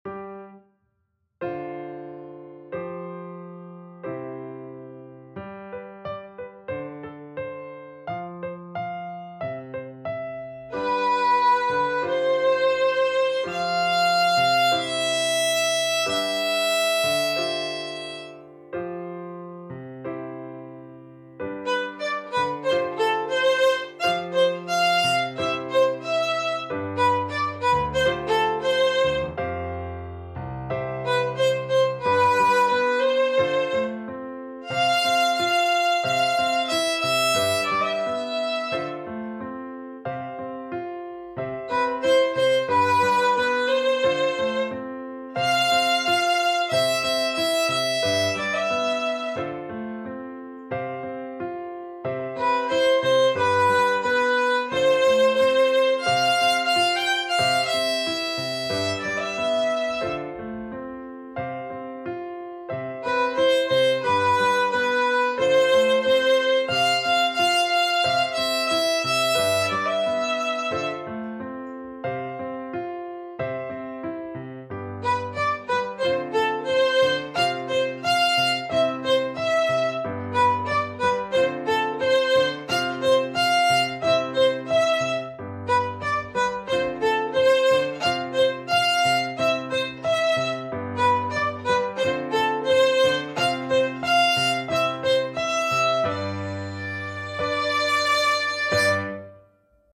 • violin + piano accompaniment